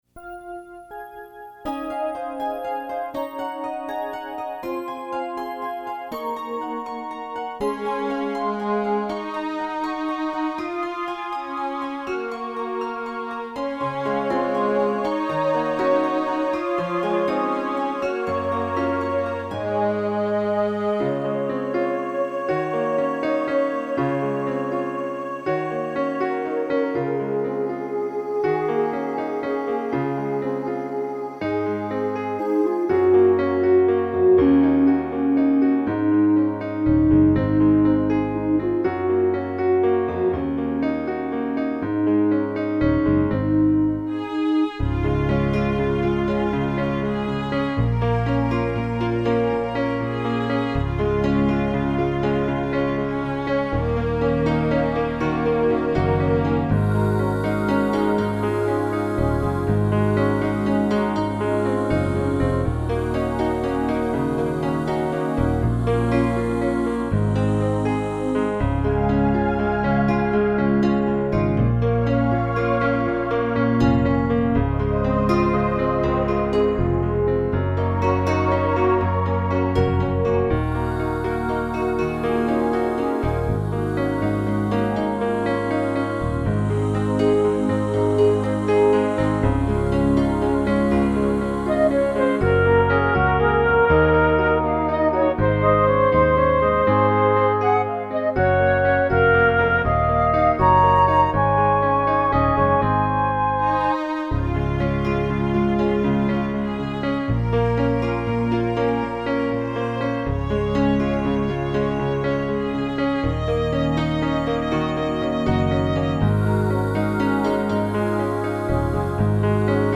The joyful melody danced in my mind and the chords were very sweet.
This song was all about joy.
WATCHING YOU GROW – Arrangement in Progress
watching-you-grow-midi-for-blog.mp3